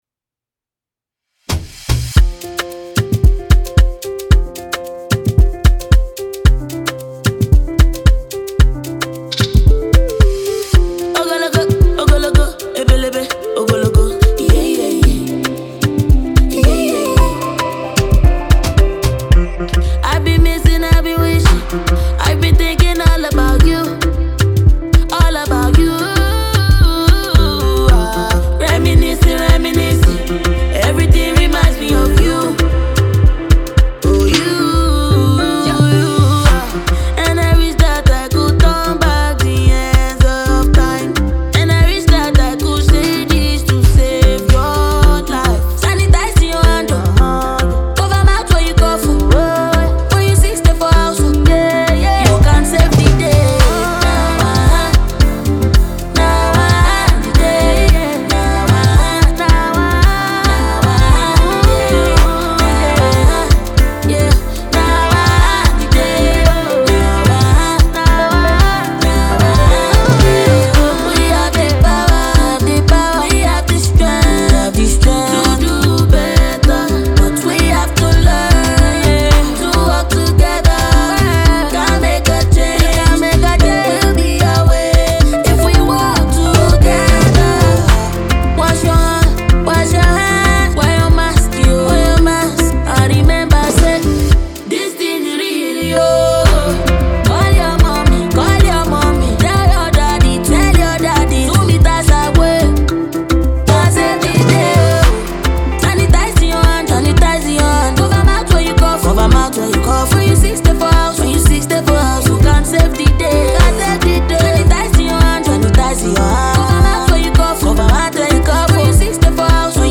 Talented Nigerian female singer